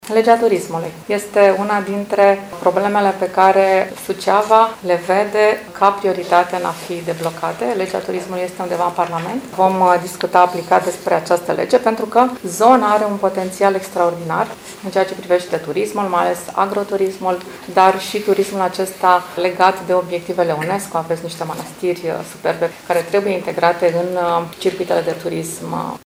În cadrul unei conferințe de presă susținute astăzi la sediul PLUS Suceava, președinta Senatului, ANCA DRAGU, a declarat că a purtat discuții cu prefectul IULIAN CIMPOEȘU și cu președintele Consiliului Județean GHEORGHE FLUTUR în legătură cu dezvoltarea turismului.